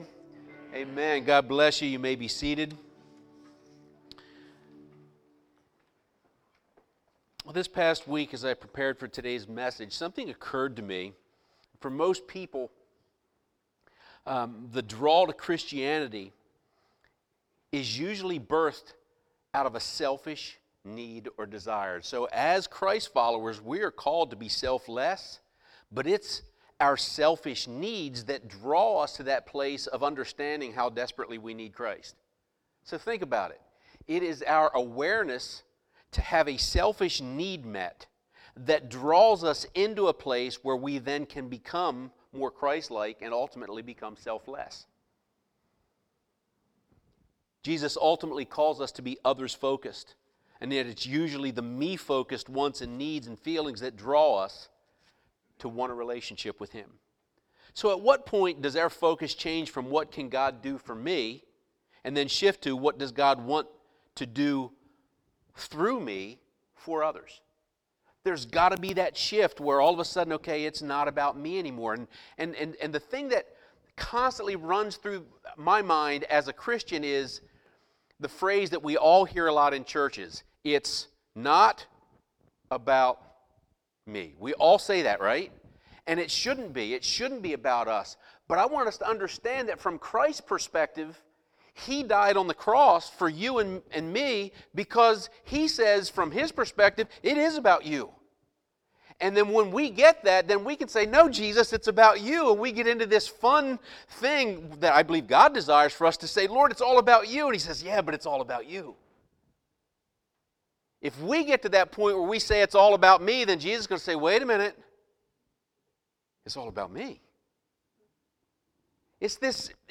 7-14-19-Sermon.mp3